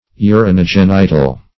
Search Result for " urinogenital" : The Collaborative International Dictionary of English v.0.48: Urinogenital \U`ri*no*gen"i*tal\, a. (Anat.)
urinogenital.mp3